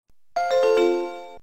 Index of /phonetones/unzipped/LG/KU310/Default sounds
Disconnect.aac